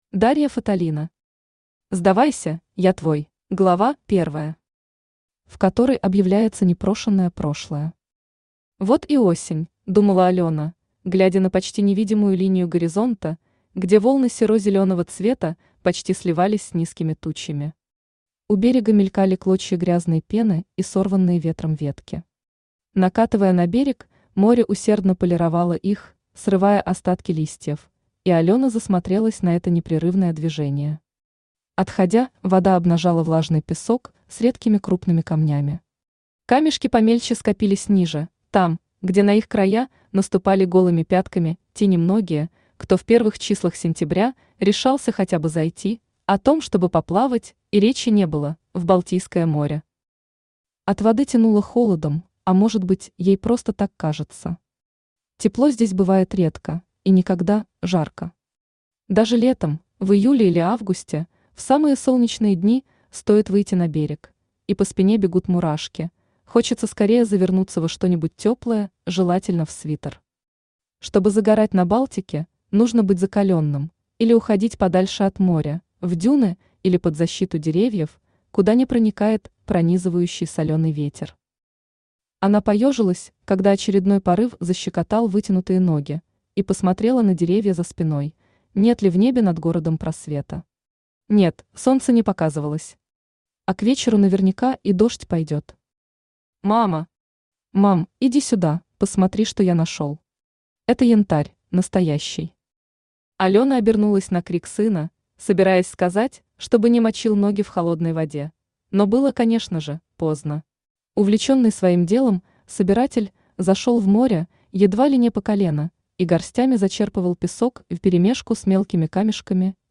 Аудиокнига Сдавайся, я твой | Библиотека аудиокниг
Aудиокнига Сдавайся, я твой Автор Дарья Фаталина Читает аудиокнигу Авточтец ЛитРес.